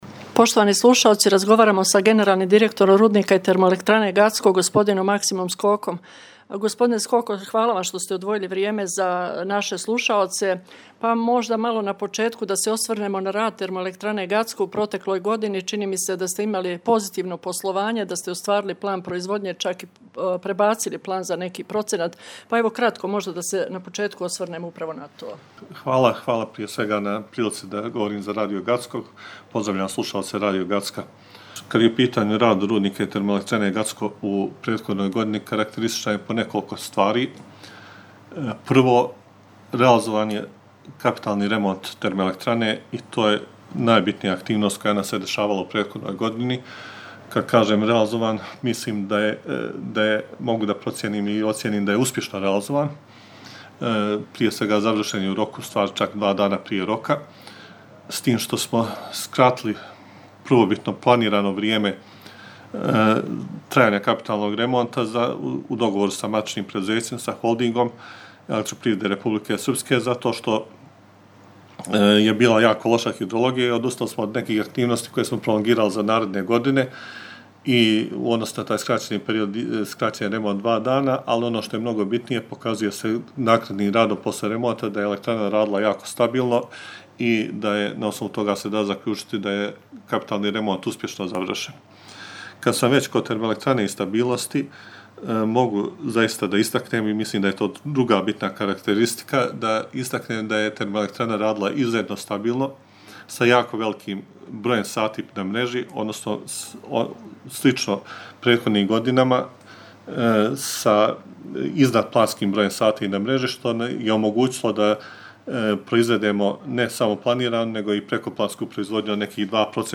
Комплетан интервју